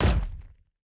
boss_walk.ogg